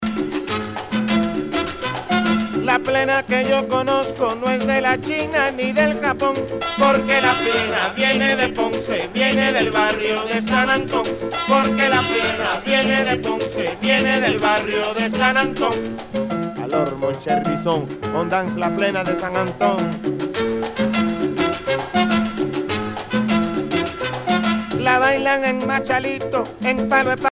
danse : bamba
Pièce musicale éditée